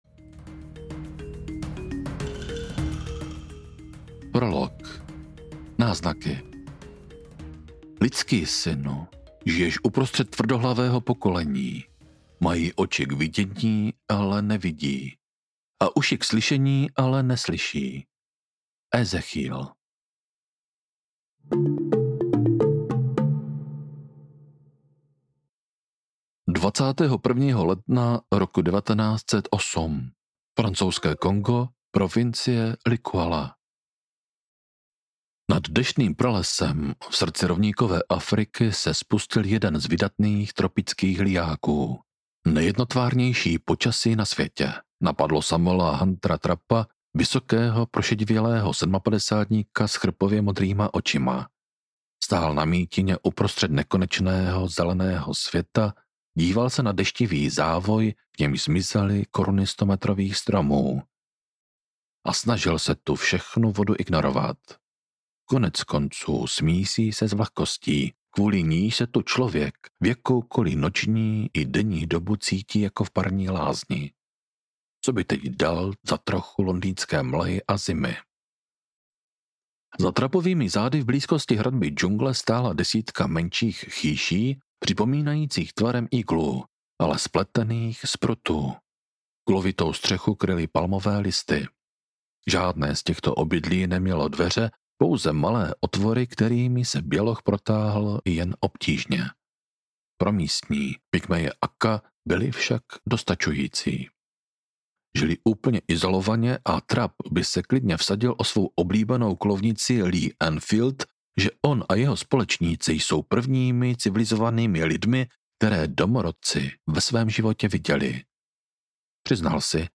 Démoni pralesa audiokniha
Ukázka z knihy